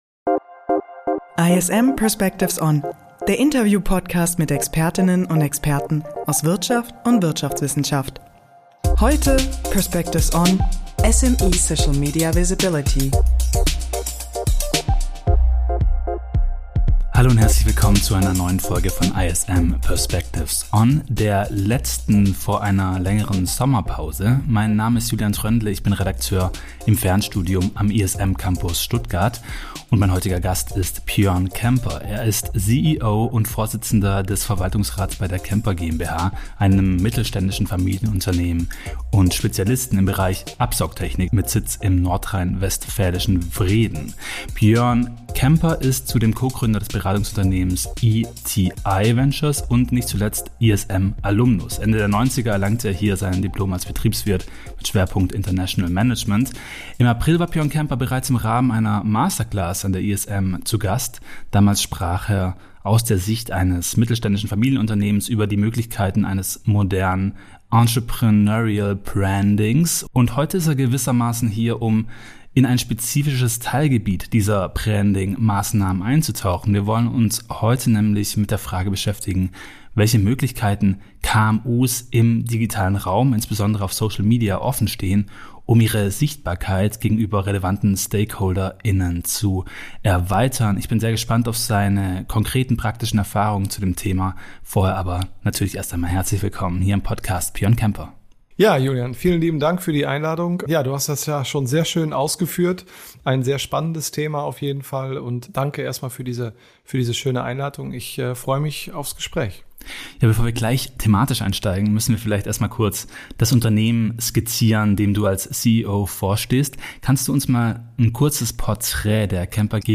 Das alles und mehr ist Gegenstand unseres Gesprächs.